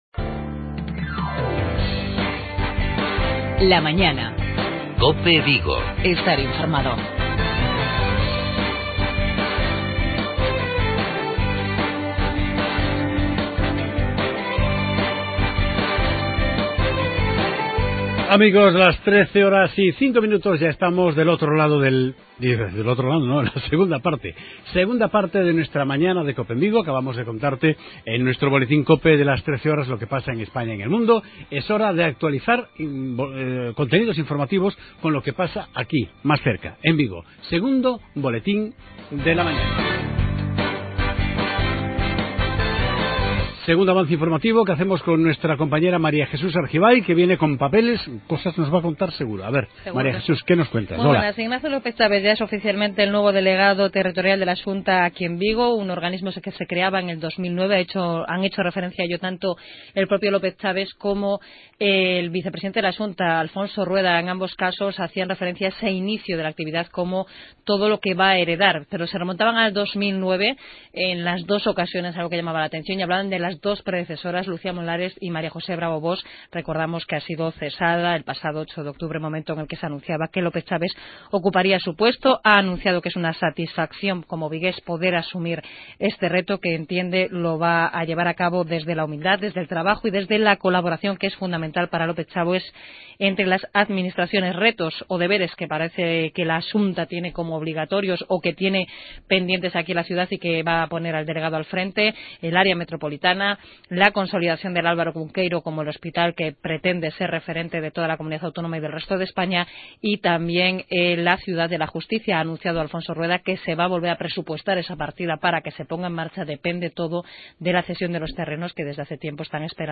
AUDIO: Avance informativoEntrevista a:Jesús María Fernández(Alcalde de O Rosal))